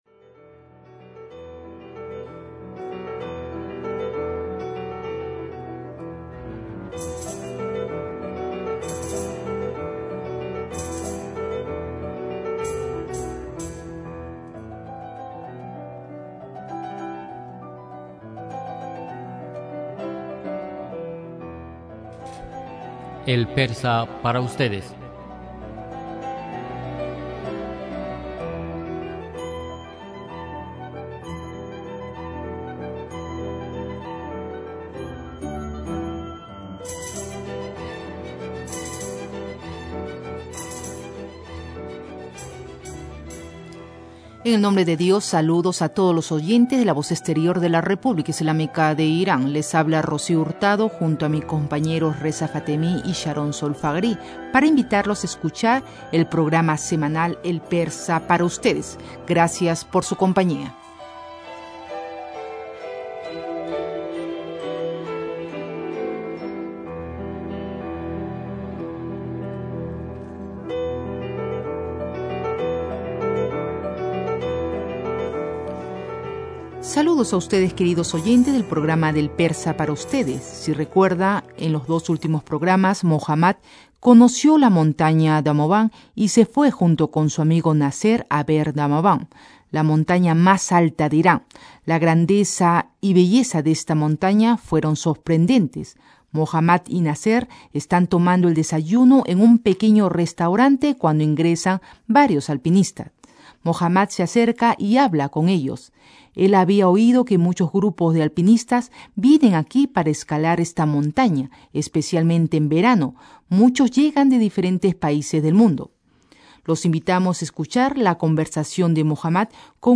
Les invitamos a escuchar la conversación de Mohammad con uno de los alpinistas.